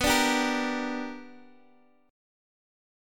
BmM7#5 chord